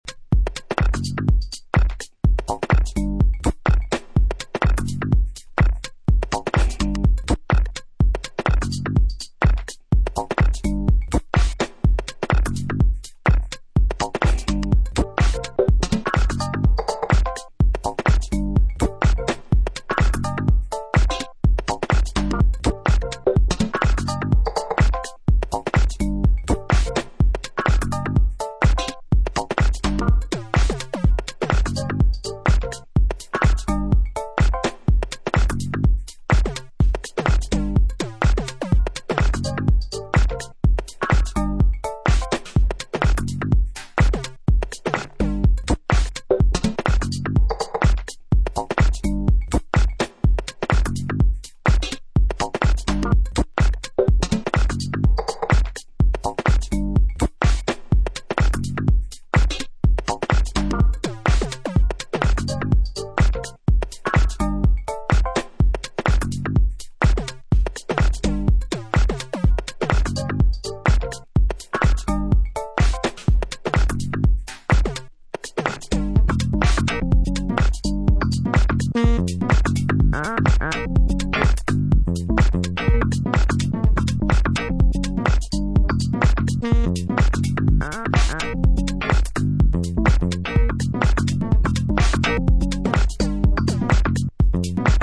4 strong minimal house tracks